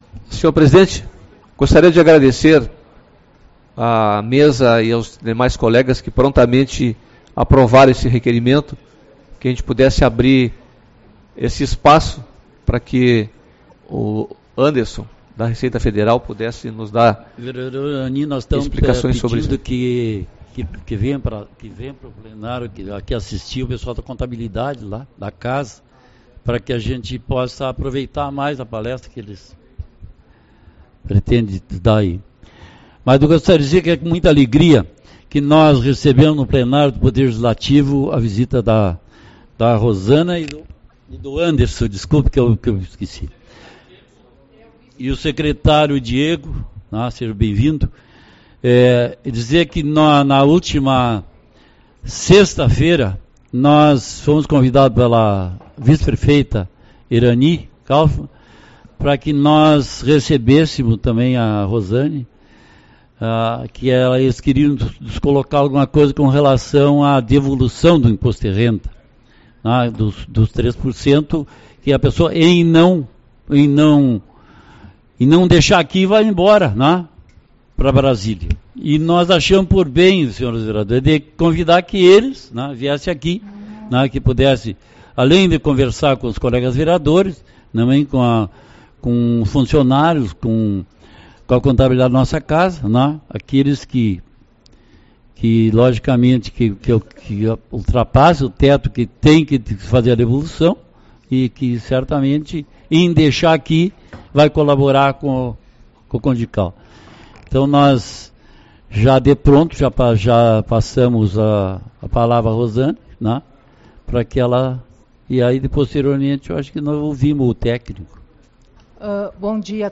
12/04 - Reunião Ordinária